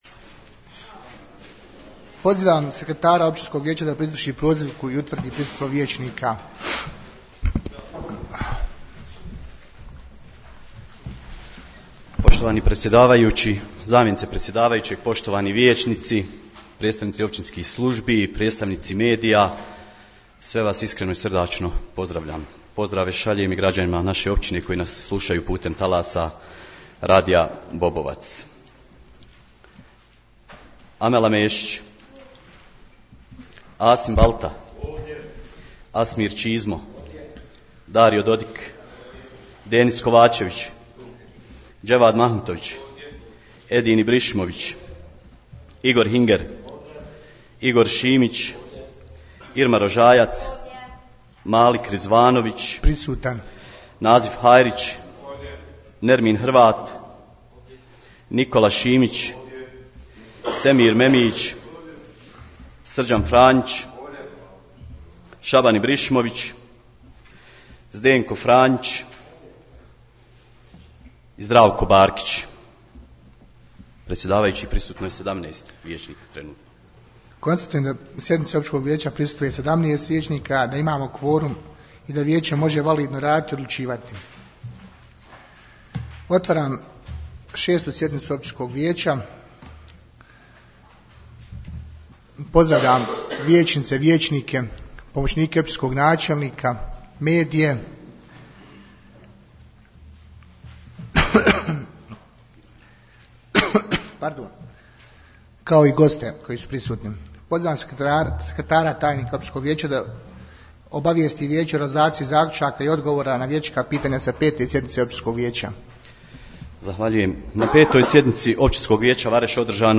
U dvorani Općine Vareš održana je 6. sjednica Općinskog vijeća Vareš na kojoj je bilo 11 točaka, poslušajte tonski zapis ....